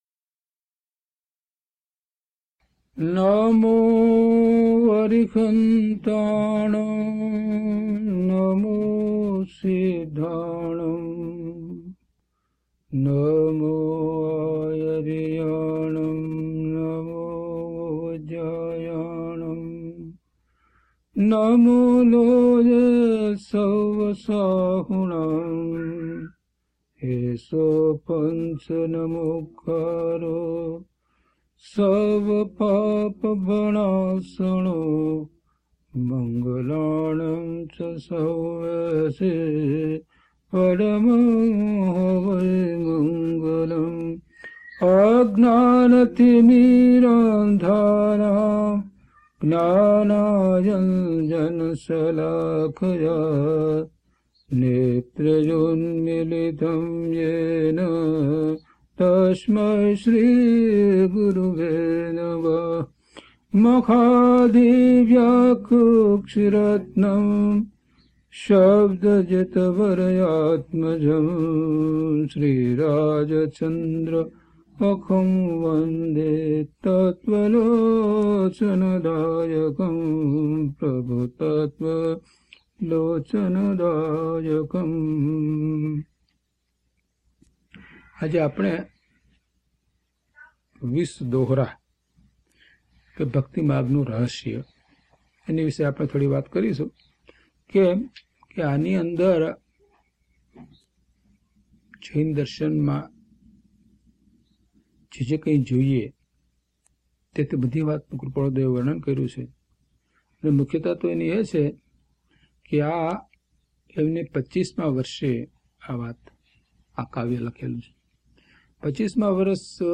DHP003 He Prabhu He Prabhu Gatha 1 to 4 - Pravachan.mp3